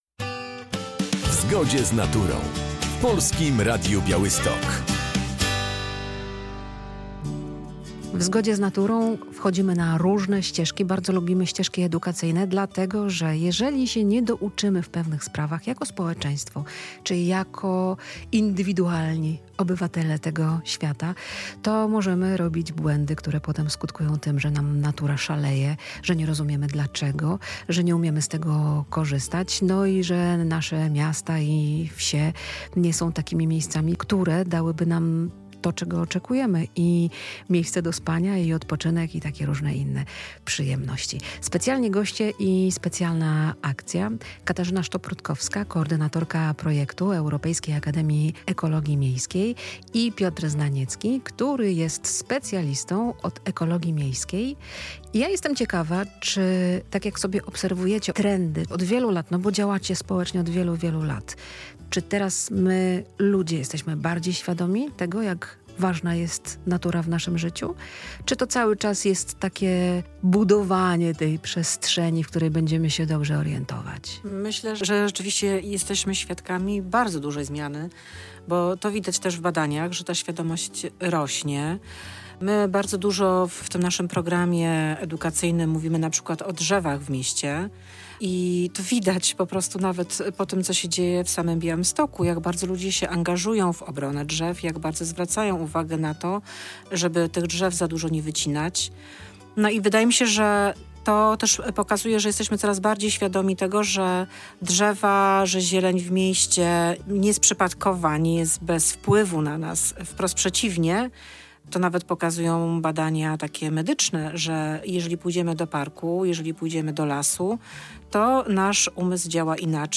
Audycja poświęcona jest ekologii miejskiej i projektowi Europejskiej Akademii Ekologii Miejskiej EURECA. Goście opowiadają o tym, jak miasta mogą stać się bardziej przyjazne naturze poprzez praktyczne działania i edukację.